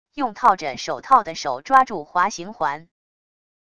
用套着手套的手抓住滑行环wav音频